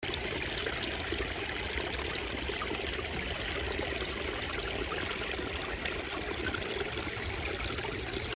Stream pour faire pipi 38568
• Catégorie: Bruits d'eau pour faire pipi 1195